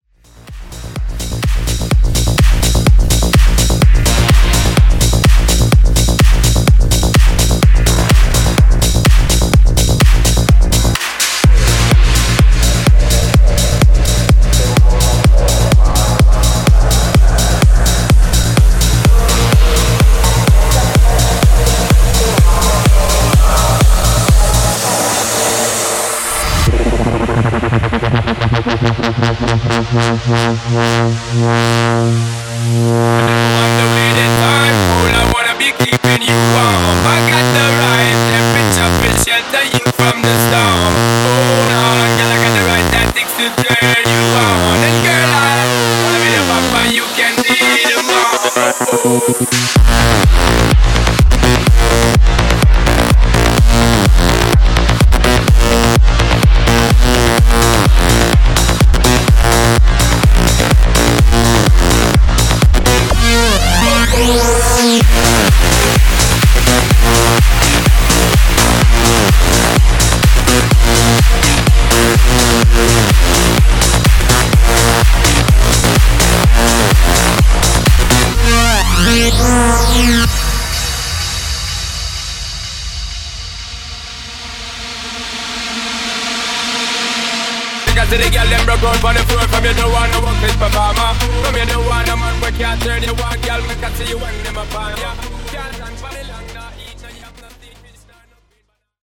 Epic Festival Mashup)Date Added